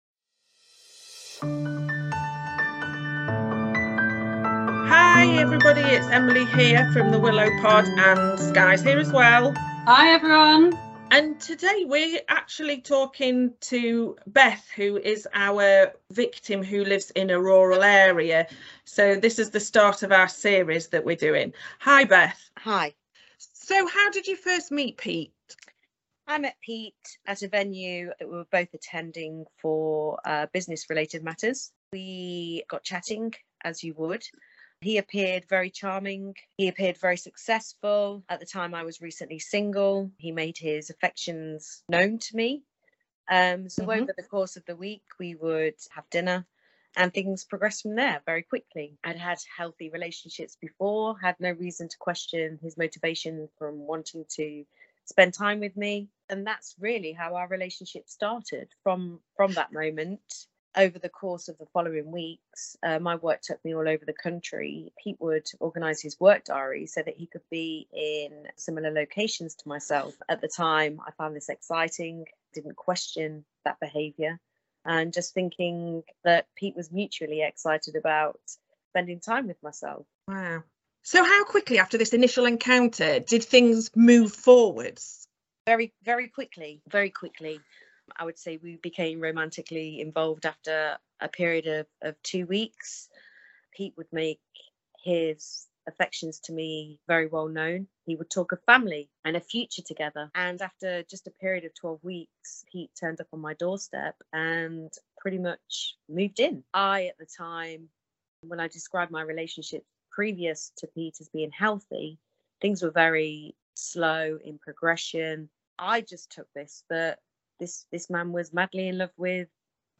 Interview with a victim episode 1